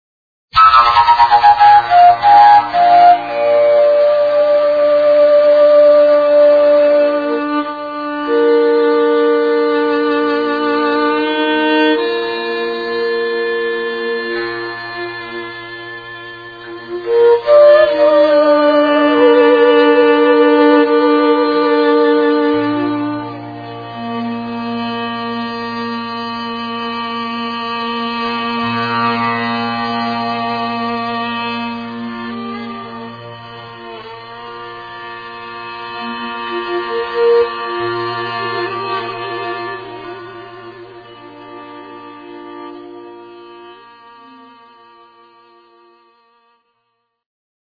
For those, who are not "in the picture" - Fujara is Slovak traditional wood instrument (a thing looking like a "big pipe"), with characteristic, unreplaceable sound. Here are my fujara´s improvisations with different musicians, alone, live, studio, etc...
fujara / tampura, percussion
violin